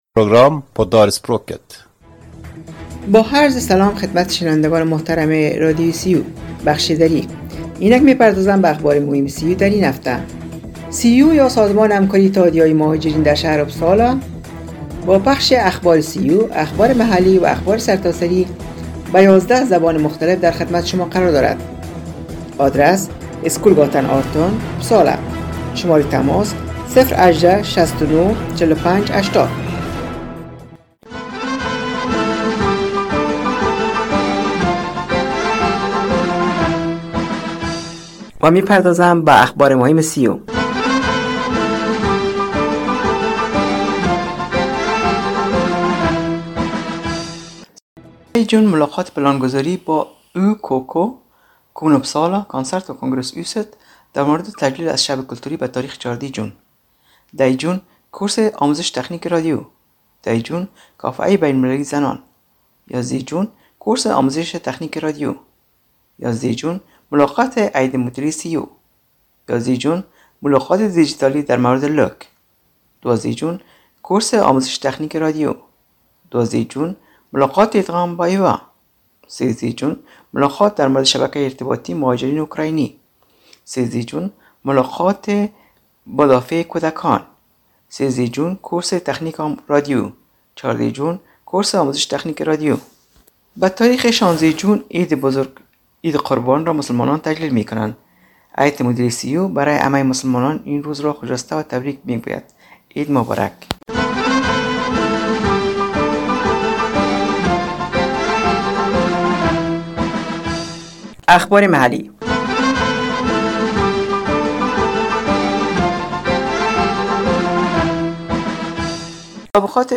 شنوندگان گرامی برنامه دری رادیو ریو یا انترنشنال رادیو در اپسالا سویدن روی موج ۹۸،۹ FM شنبه ها ازساعت ۸:۳۰ تا ۹ شب به وقت سویدن پخش میگردد که شامل اخبار سیو، اخبارمحلی و اخبارسرتاسری میباشد